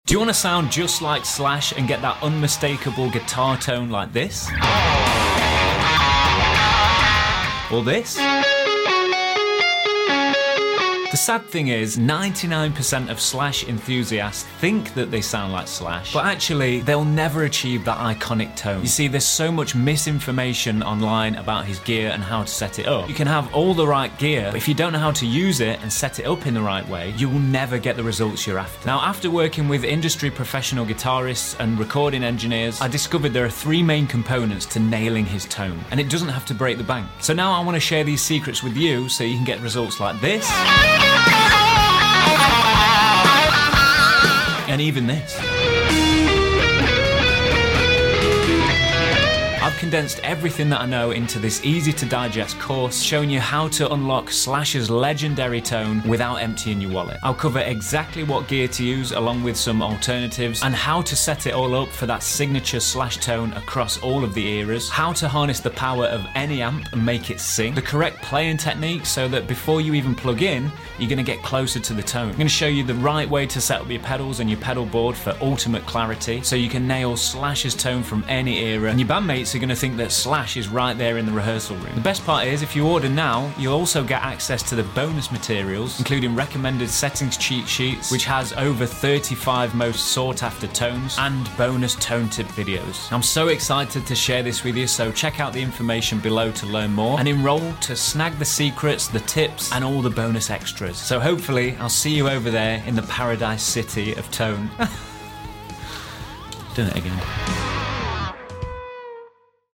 🤩🤘🏻🎸 It shows you exactly how to achieve all of your favourite Slash tones from across the eras! Visit the website and register so you don’t miss out on the early bird special 😉 I can’t wait for you to try these tones on your rig…